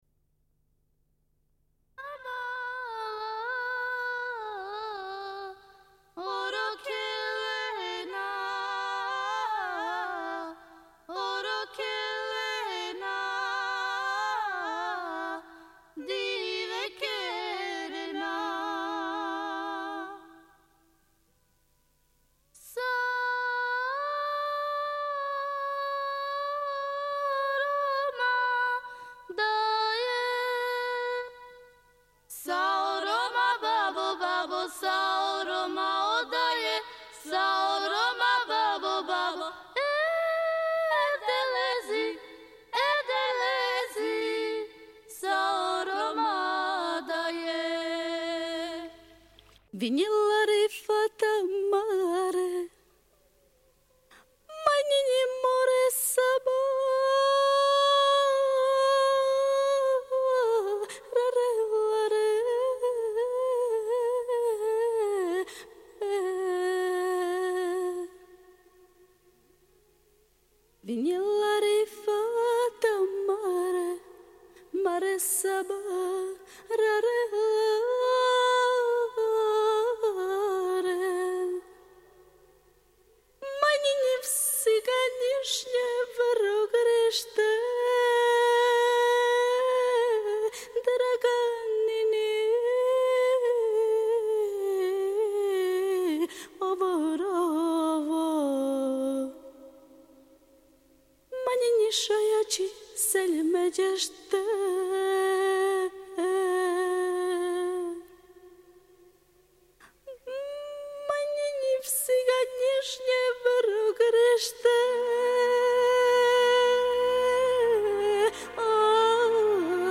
Émission musicale.